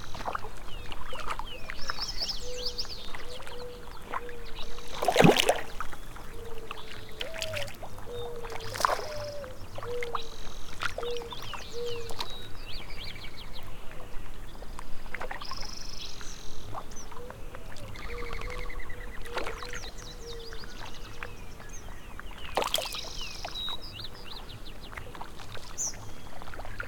lake.ogg